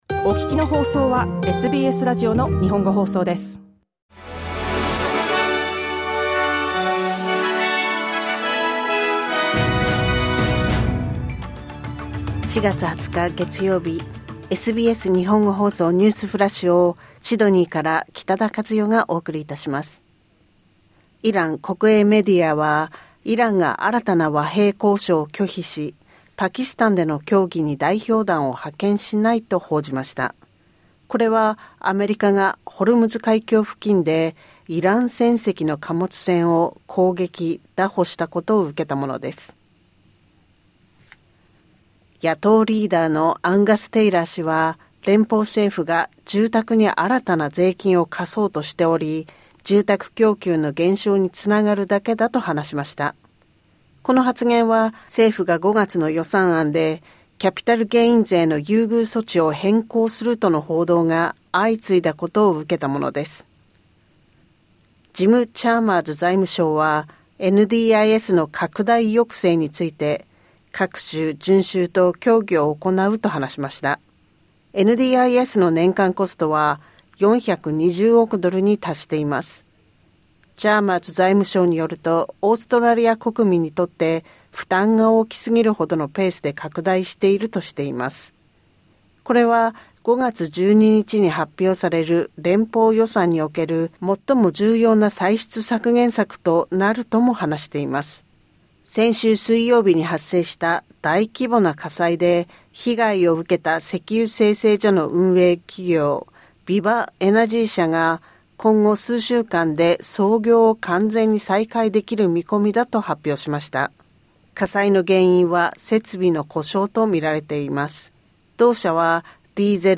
SBS Japanese Newsflash Monday 13 April